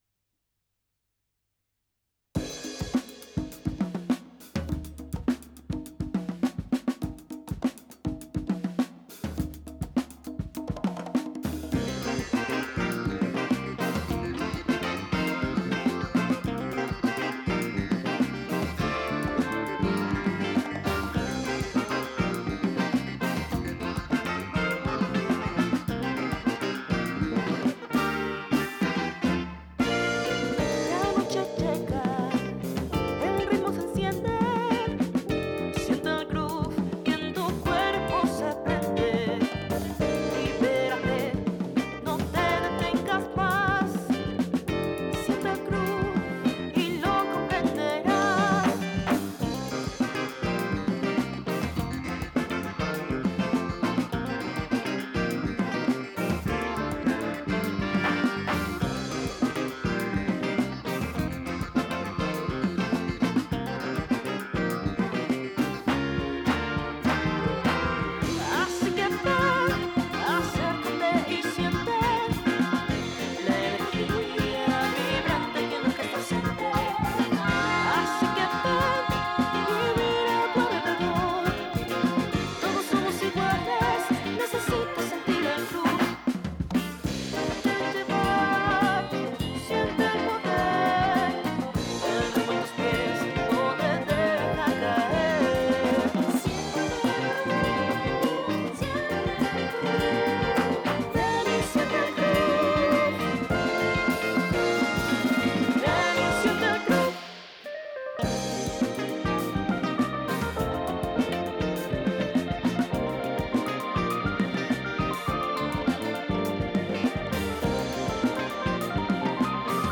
Funk colombiano
Emulación analógica